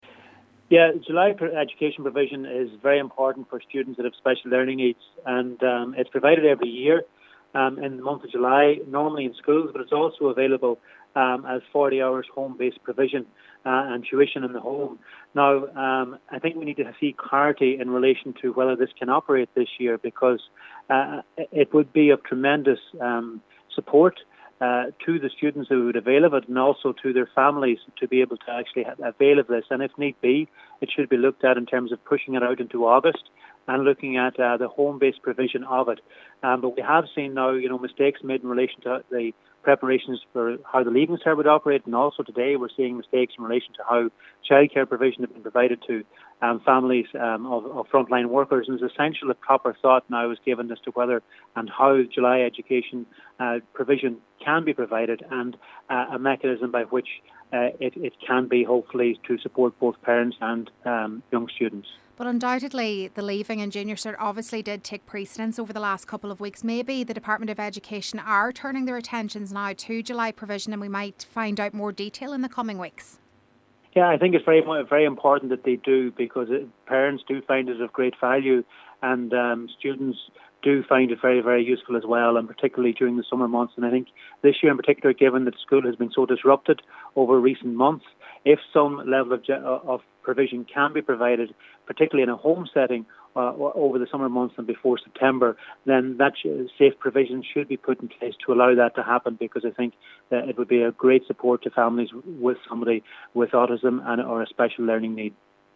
He’s calling on Minister Joe McHugh to give guidance: